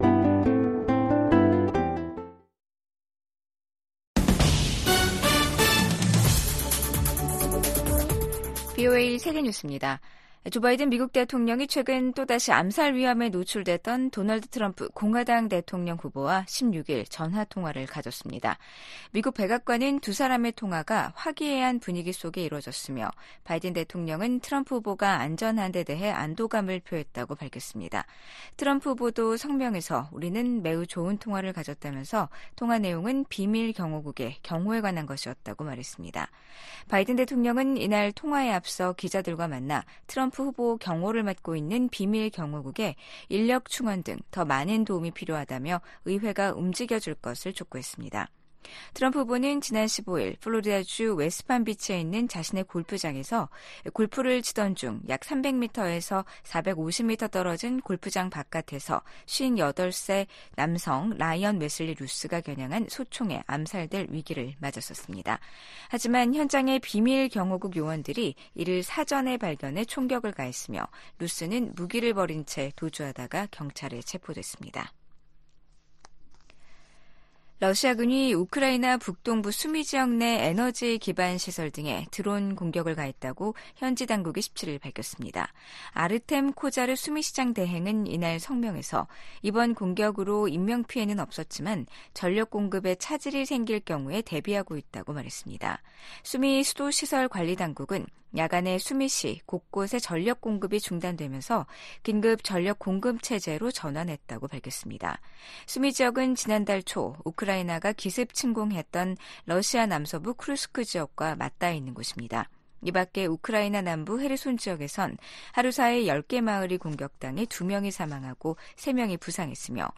VOA 한국어 아침 뉴스 프로그램 '워싱턴 뉴스 광장' 2024년 9월 18일 방송입니다. 스웨덴이 신종 코로나에 따른 북한의 국경 봉쇄 조치 이후 서방 국가로는 처음으로 외교관들을 북한에 복귀시켰습니다. 국제원자력기구(IAEA) 총회가 개막한 가운데 북한의 지속적인 핵 개발은 명백한 유엔 안보리 결의 위반이라고 IAEA 사무총장이 지적했습니다. 유럽연합이 최근 우라늄 농축시설을 공개한 북한에 대해 결코 핵보유국 지위를 가질 수 없다고 지적했습니다.